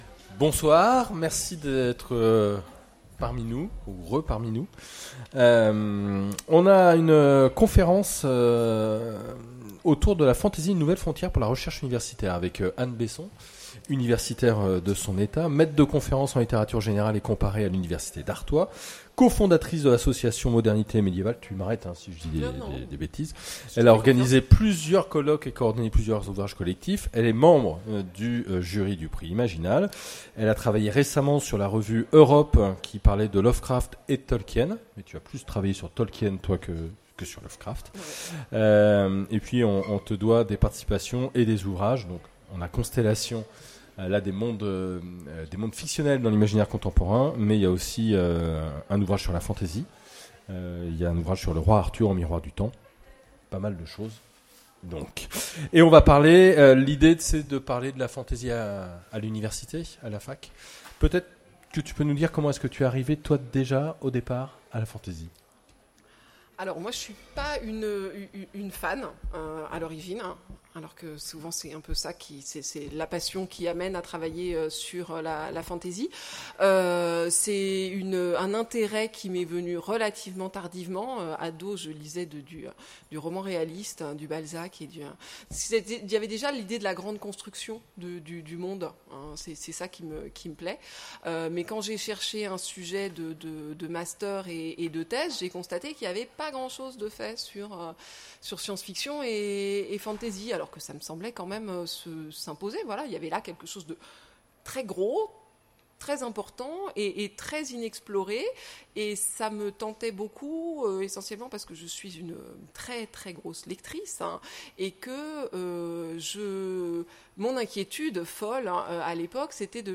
Imaginales 2016 : Conférence La fantasy, une nouvelle frontière…